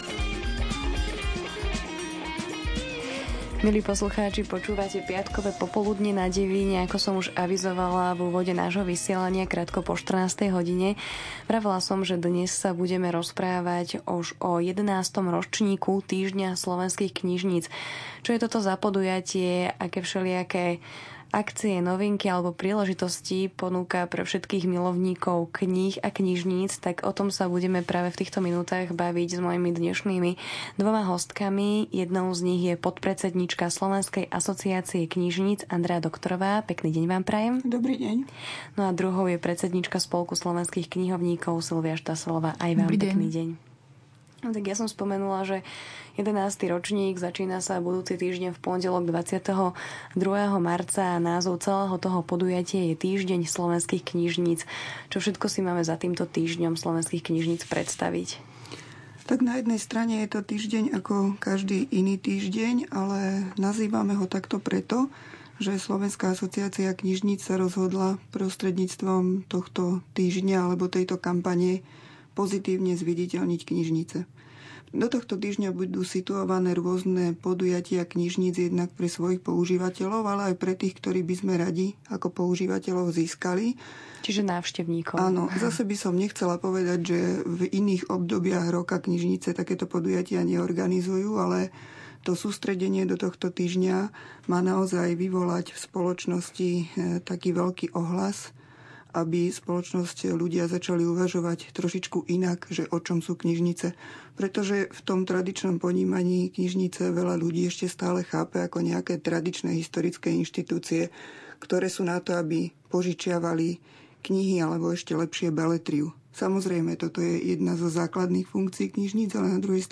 Vypočujte si záznam živého rozhovoru na zaujímavé témy, linka na audiozáznam v aktualite na portále.
Rozhovor o slovenských knižniciach na stiahnutie